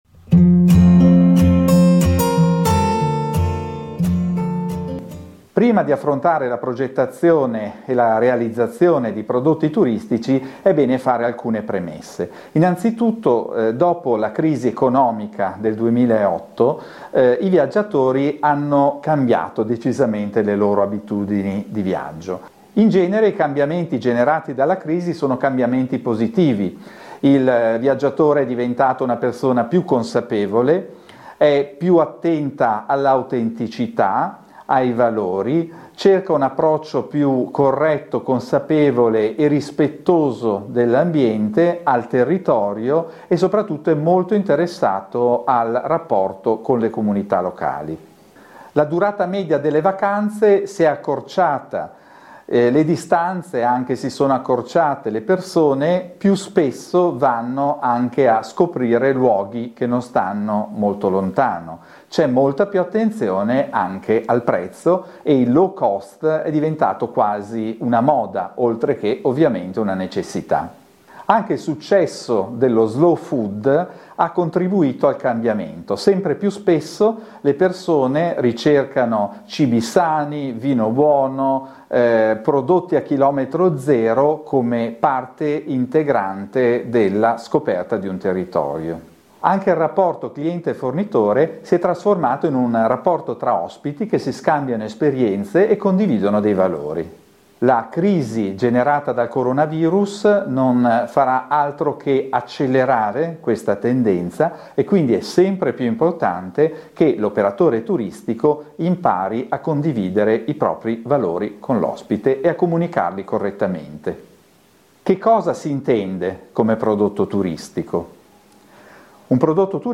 La crisi del 2020 dovuta al Corona Virus quali conseguenze provocherà in ambito turistico?In questa video lezione andremo a rispondere a queste domande, analizzando anche quali sono l'esigenze del turista al giorno d'oggi.